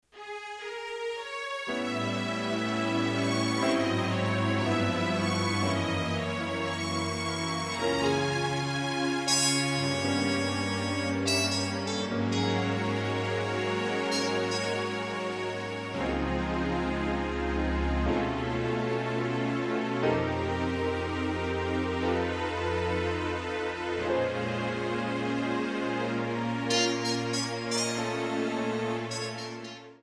Db/D
MPEG 1 Layer 3 (Stereo)
Backing track Karaoke
Pop, Jazz/Big Band, Duets, 1990s